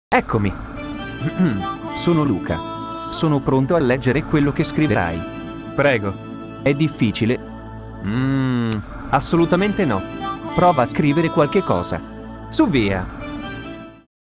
La particolarità dei suoi prodotti è l'estrema qualità della sintesi vocale, che ad un orecchio distratto può sembrare una registrazione ad hoc, mentre invece è sintetizzata direttamente dal computer.
file di demo. Come potete sentire la qualità è elevatissima, e potete fare pure voi degli esperimenti, scrivendo delle frasi da generare sulla demo interattiva di Loquendo.